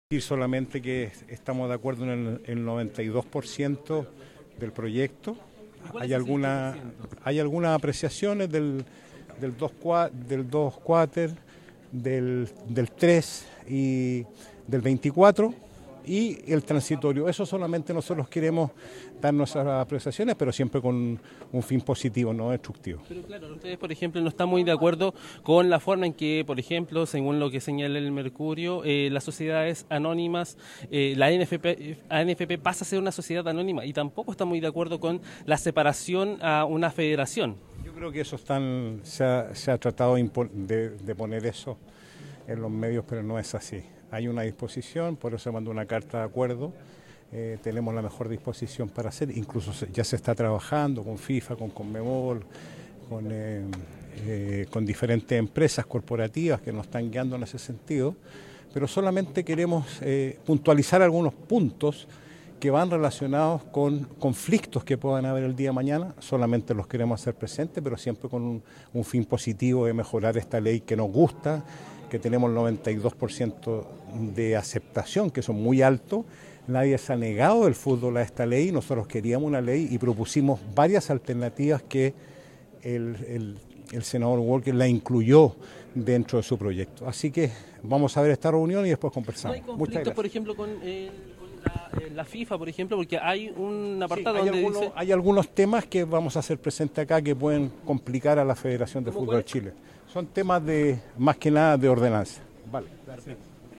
La Cámara de Diputados retomó este martes el debate de la ley de Sociedades Anónimas Deportivas (SADP) en Chile. La sesión incluyó la participación del presidente de la ANFP, Pablo Milad, y la ministra del Deporte, Natalia Ducó, en una jornada clave para la continuidad del proyecto.